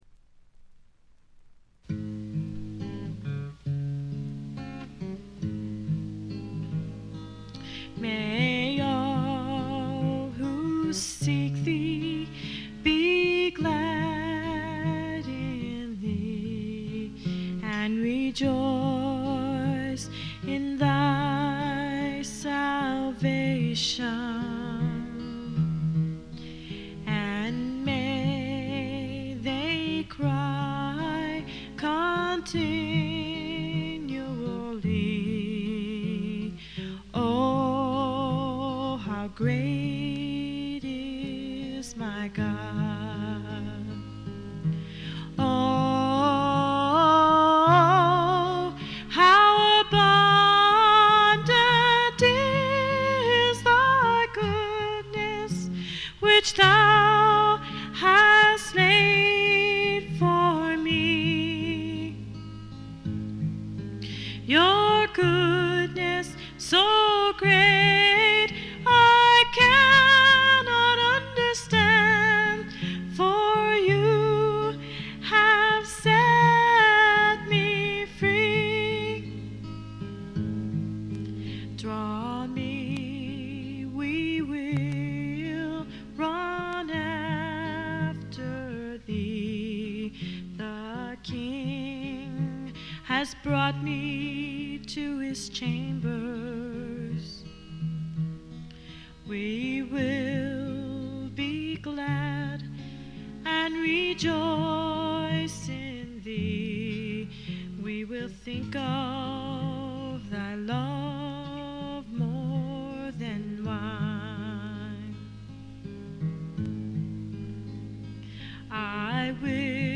知られざるクリスチャン・フォーク自主制作盤の快作です。
試聴曲は現品からの取り込み音源です。